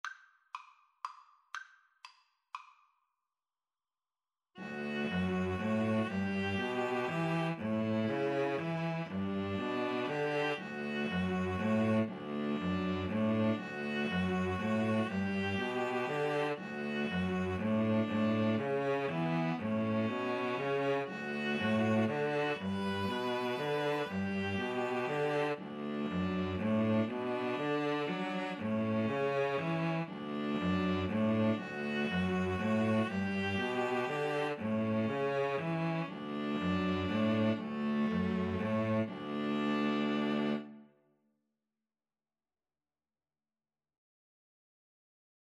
3/4 (View more 3/4 Music)
D major (Sounding Pitch) (View more D major Music for String trio )
= 120 Slow one in a bar
String trio  (View more Easy String trio Music)